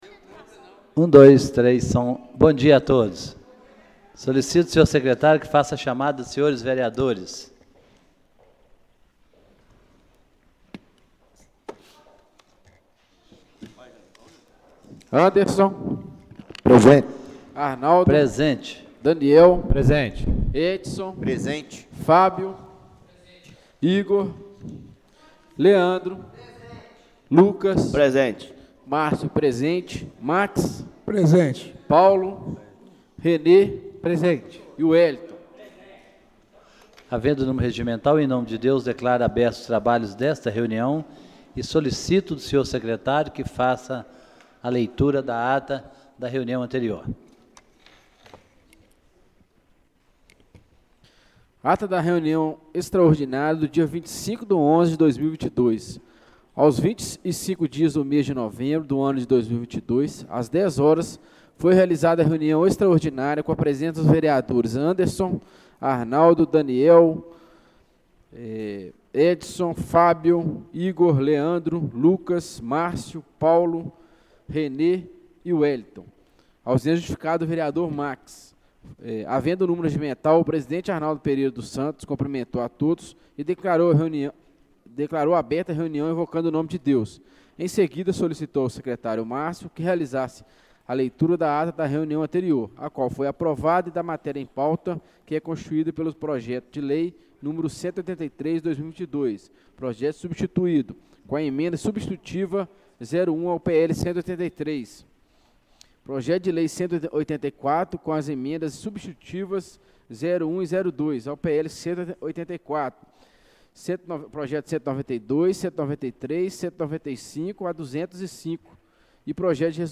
Reunião Extraordinária do dia 28/11/2022